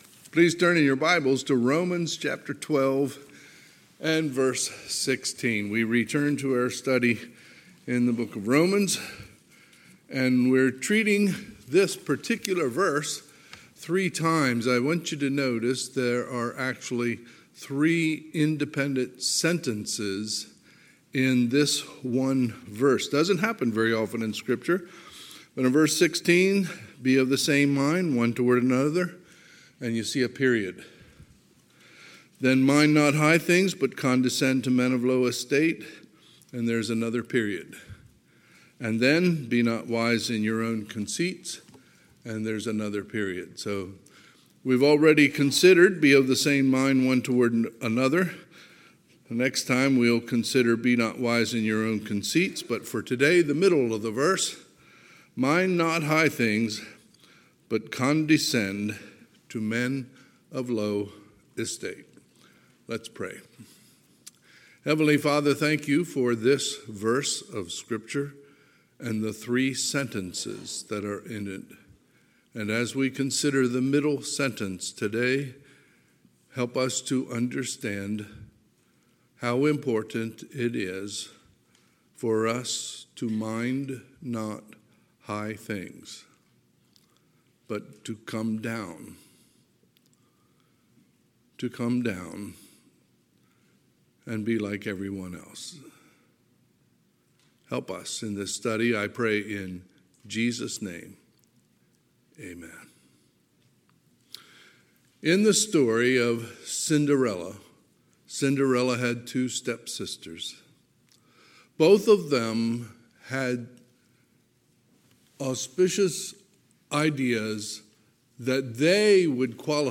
Sunday, September 11, 2022 – Sunday AM
Sermons